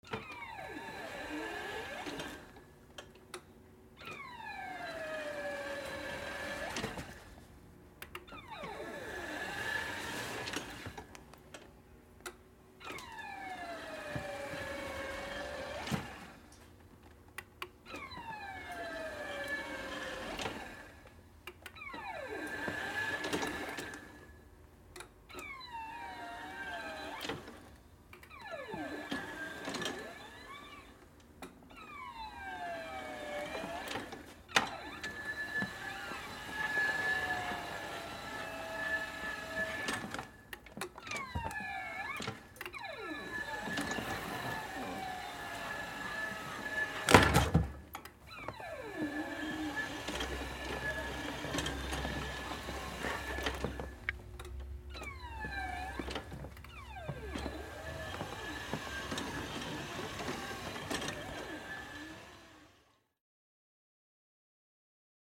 Звуки инвалидной коляски
Шум электроколяски